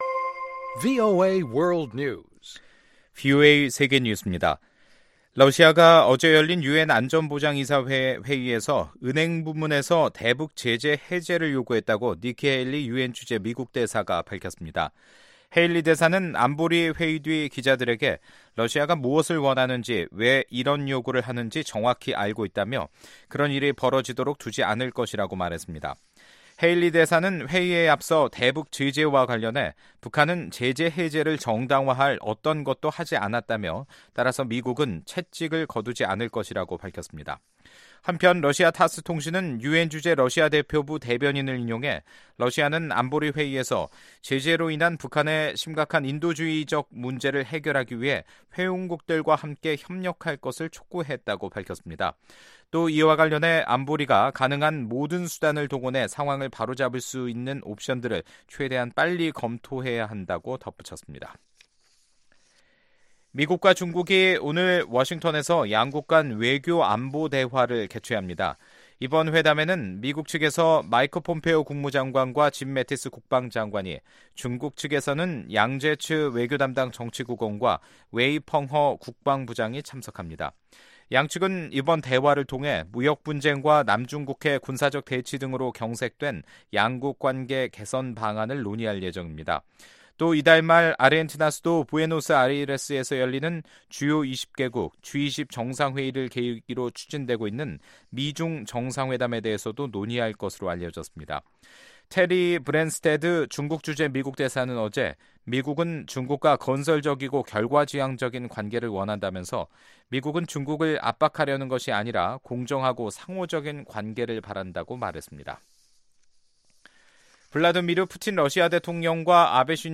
VOA 한국어 간판 뉴스 프로그램 '뉴스 투데이', 2018년 11월 9일 2부 방송입니다. 러시아가 유엔 안보리에서 대북 금융제재 완화를 요구한데 대해 미국은 거부 의사를 분명히 했습니다. 유엔 안보리 대북제재위원회가 유엔아동기금에 인도주의 물품의 대북 반입을 허가했습니다. 미국 하원 다수당이된 민주당 의원들이 앞으로 북한 청문회를 요구할 것이라고 외교전문지가 전망했습니다.